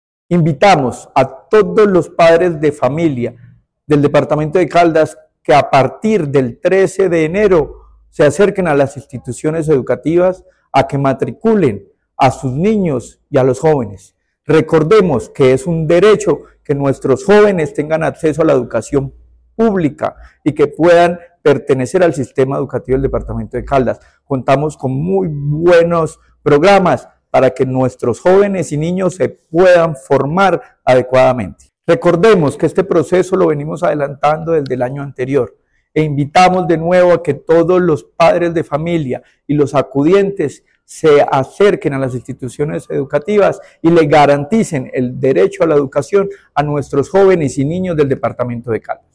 Secretario de Educación de Caldas, Luis Herney Vargas Barrera.
Secretario-de-Educacion-de-Caldas-Luis-Herney-Vargas-matriculas.mp3